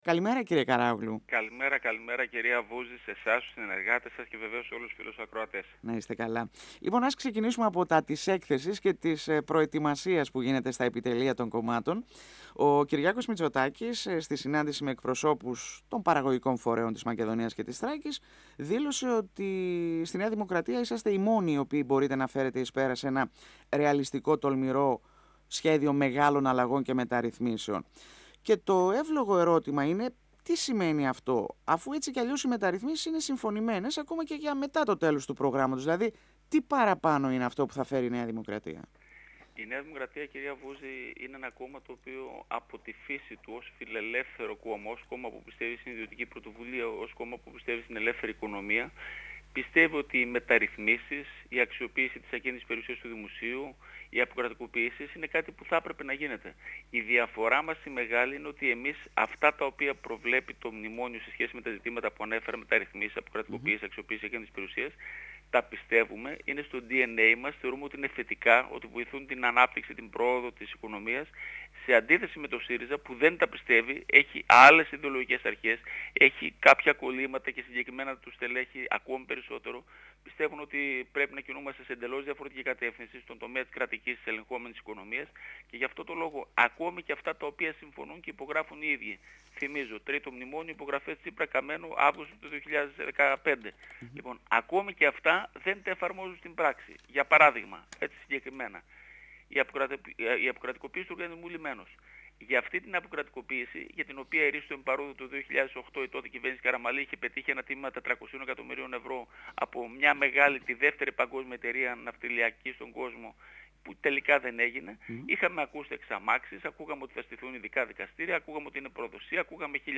05Σεπ2017 – Ο βουλευτής Β’ Θεσσαλονίκης της ΝΔ Θεόδωρος Καράογλου στον 102 fm της ΕΡΤ3